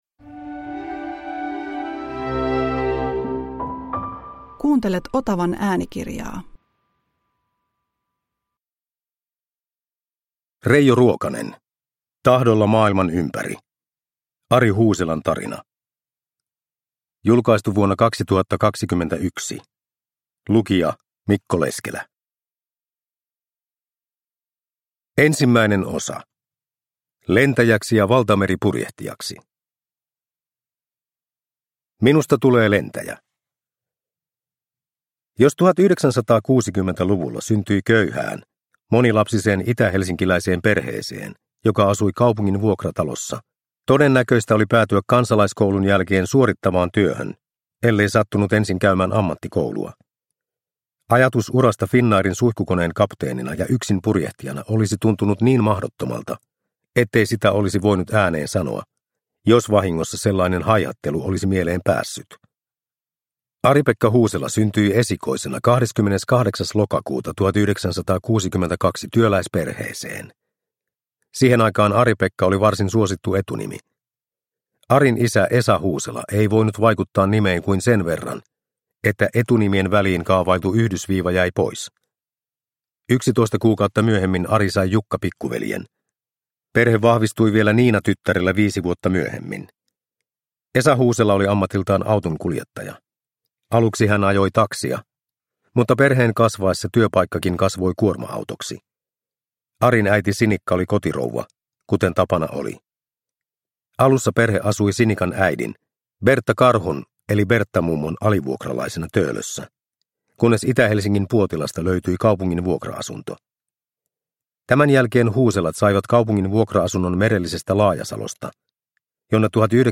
Tahdolla maailman ympäri – Ljudbok